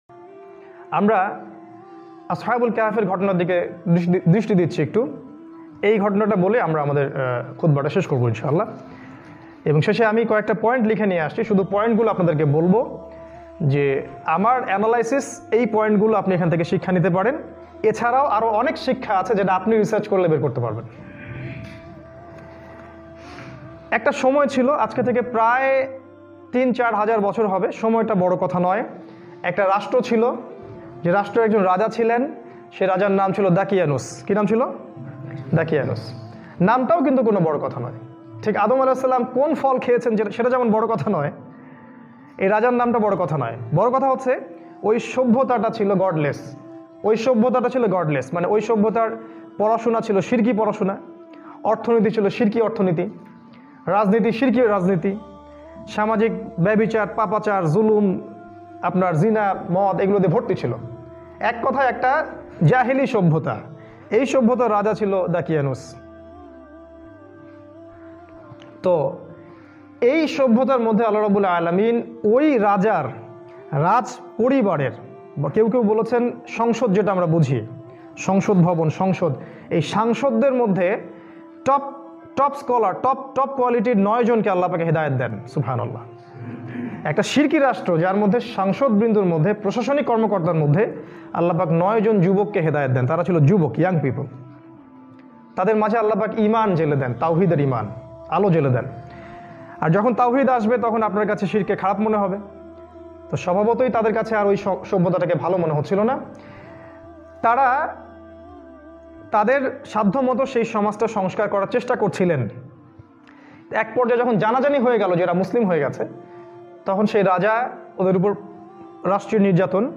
waz